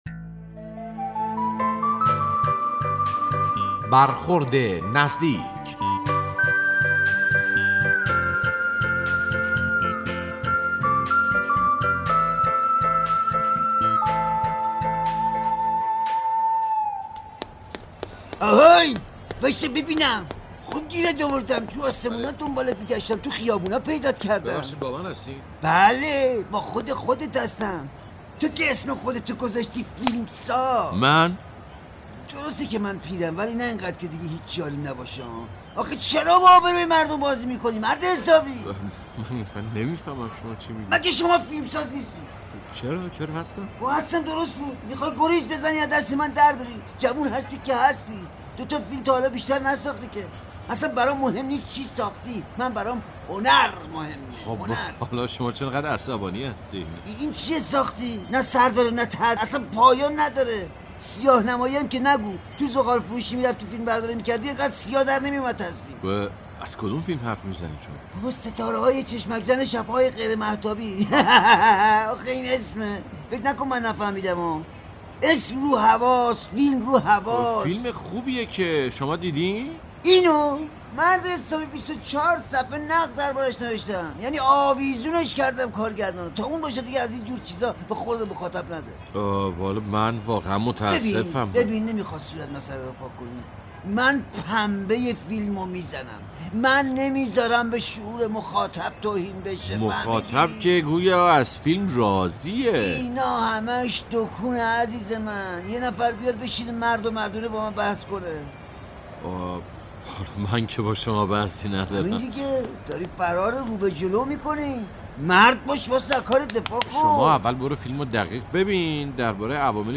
نمایش رادیویی «برخورد نزدیک» درباره مردی است که شخصی را با فیلمساز مورد نظر خود اشتباه می گیرد.